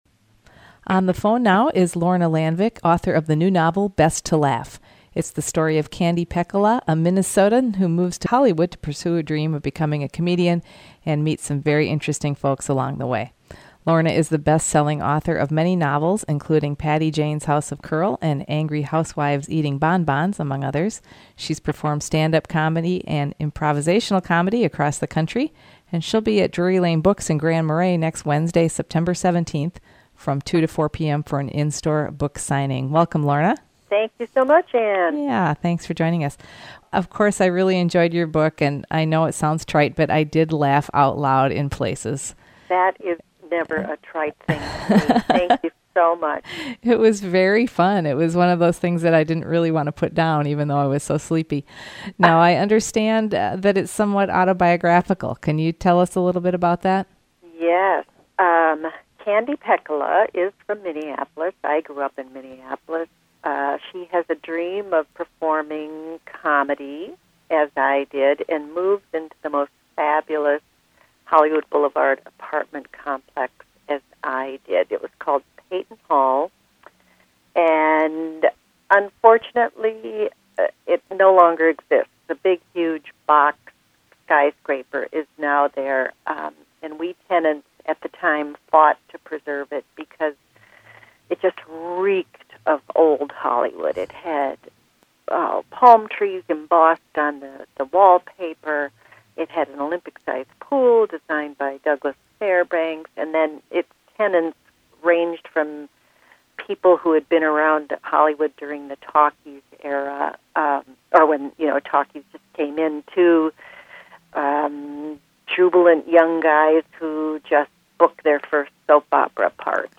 It's loosely based on her real-life experiences living in Hollywood and being a comedian, and in this interview she shares stories from her time there.